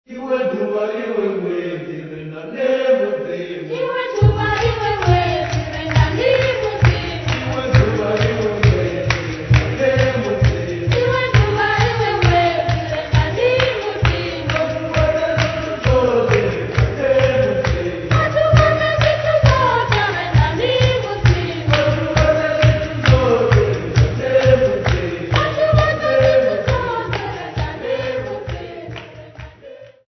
Choral music
Field recordings
Africa South Africa Louis Trichardt District f-sa
Adaptations of Church Music.
7.5 inch reel